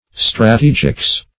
Search Result for " strategics" : Wordnet 3.0 NOUN (1) 1. the science or art of strategy ; The Collaborative International Dictionary of English v.0.48: Strategics \Stra*te"gics\, n. Strategy.
strategics.mp3